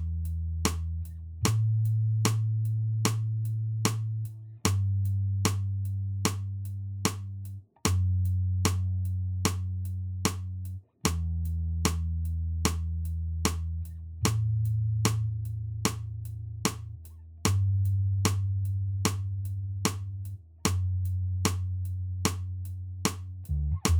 no Backing Vocals Indie / Alternative 4:52 Buy £1.50